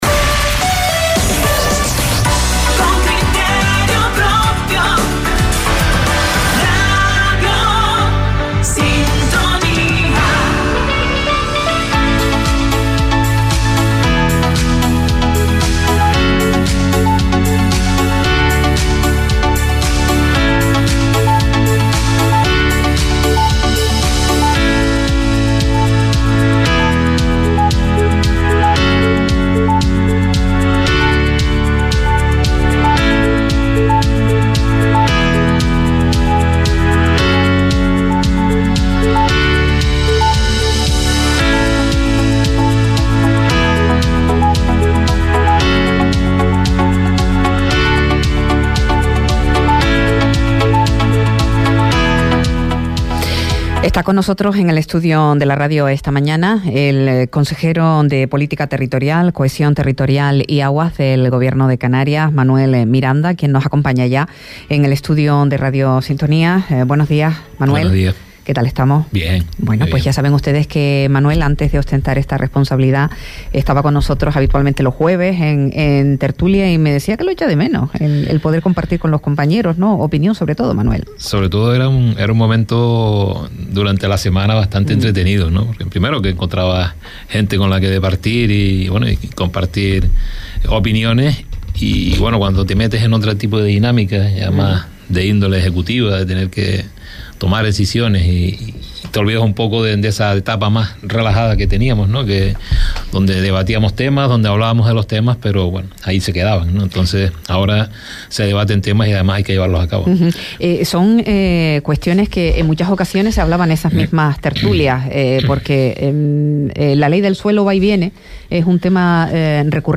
Entrevista a Manuel Miranda, consejero de Política Territorial, Cohesión Territorial y Aguas - 29.09.23 - Radio Sintonía
Entrevistas